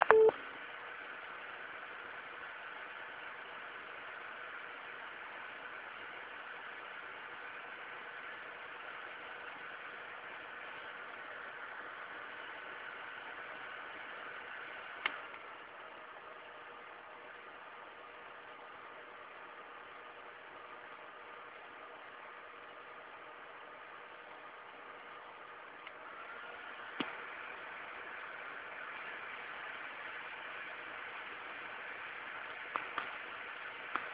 odgłosy z pracy chłodzenia w trybie HIGH, po 15 sekundach przełączam na LOW, po czym za chwilę znów na HIGH.
Słychać wyraźnie, że tryb HIGH jest dość głośny choć zdecydowanie cichszy od tego co usłyszymy na typowym Radeonie X1950XTX. Tryb LOW również do cichych nie należy ale jest już na akceptowanym przez wielu poziomie, zbliżonym na przykład do standardowego chłodzenia z GeForce'a 7900GS.
x1950xtx_toxic.wav